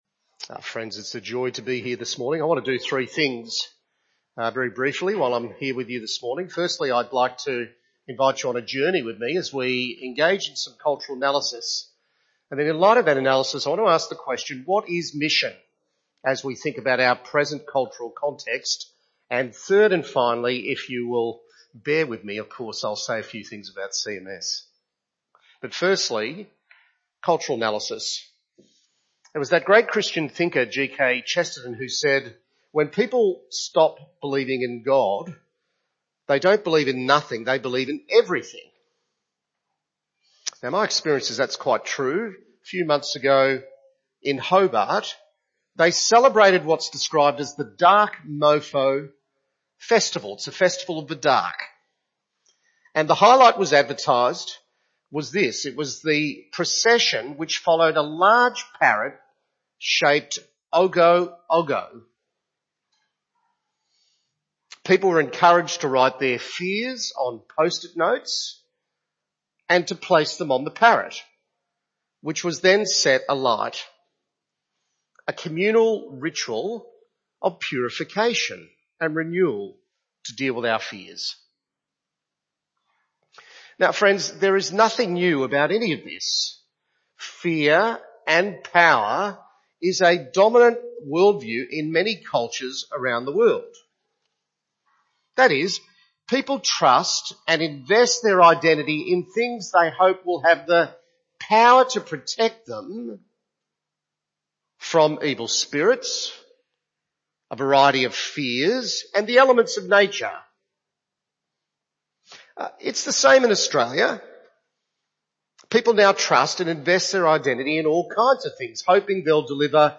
Series: A Sunday Sermon From BAC
Service Type: Sunday Morning